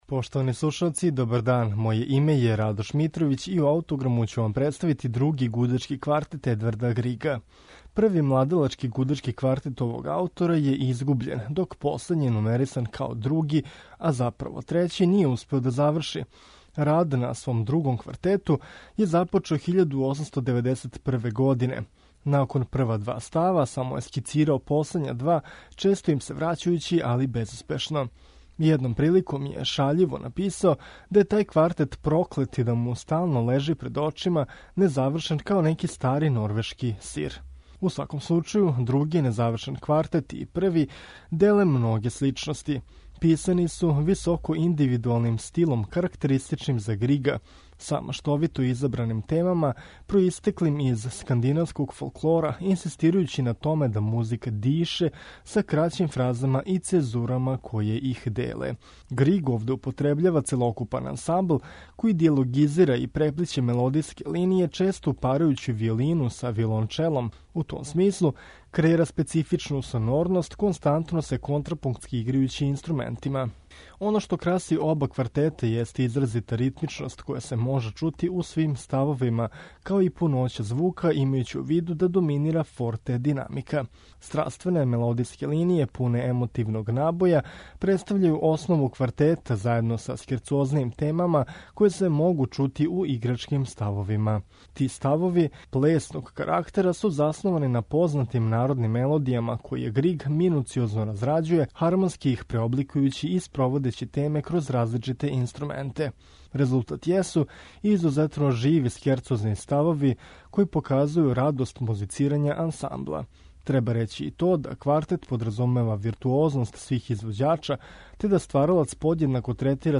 Квартет Чилингиријан
Ми ћемо га слушати у интерпретацији квартета Чилингиријан.